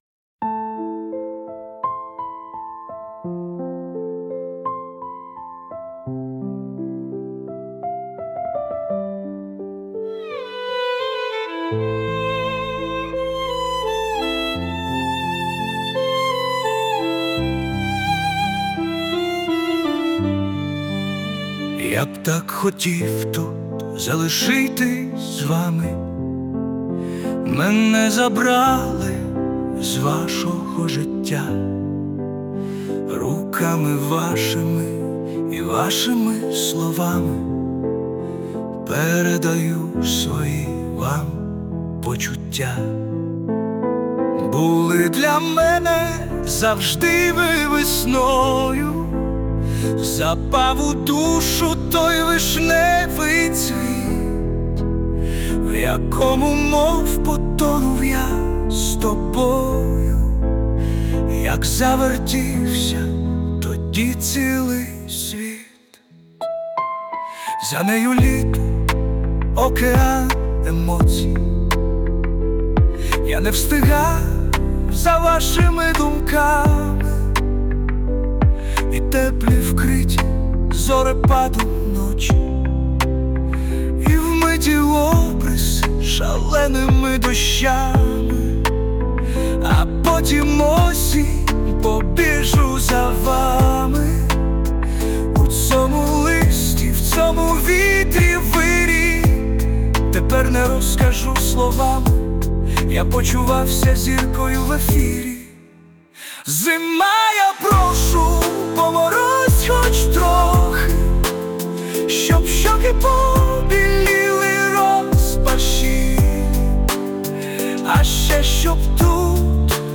Музичне прочитання з допомогою ШІ